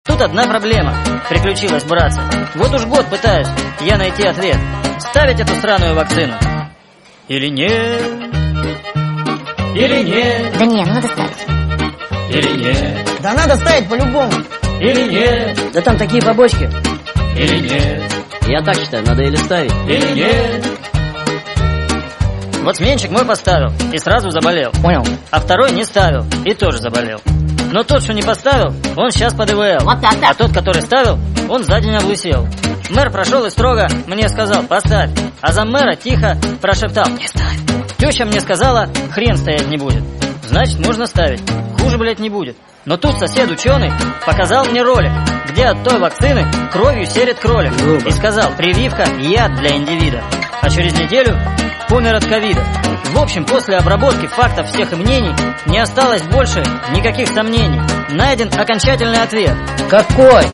мужской голос
забавные
смешные
цикличные